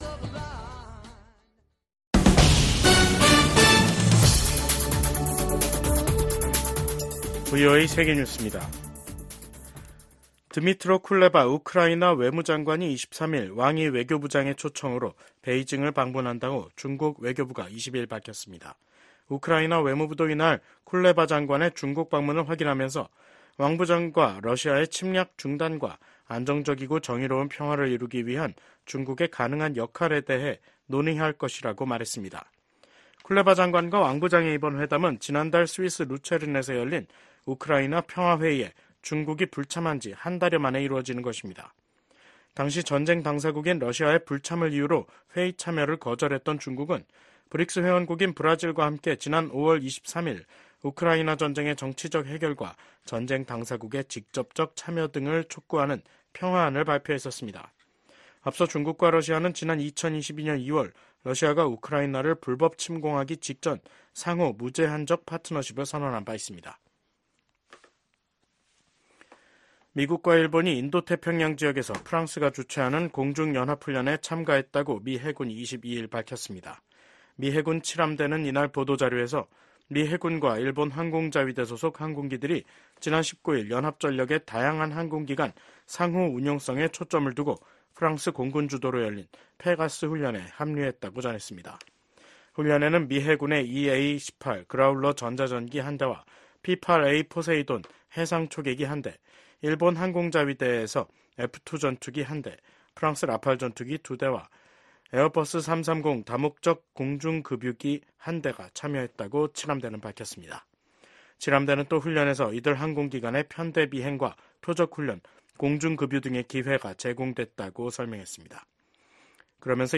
VOA 한국어 간판 뉴스 프로그램 '뉴스 투데이', 2024년 7월 22일 3부 방송입니다. 도널드 트럼프 전 대통령이 공화당 대선 후보 수락 연설에서 미국 사회의 불화와 분열이 빠르게 치유돼야 한다고 강조했습니다. 미한일 합참의장이 3국 다영역 훈련인 프리덤 에지 훈련을 확대하기로 합의했습니다. 북한 군인들이 국제법에 반하는 심각한 강제노동에 시달리고 있다는 우려가 국제사회에서 제기되고 있습니다.